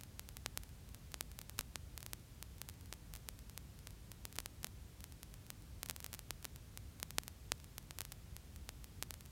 VinylOnlyAtmos.wav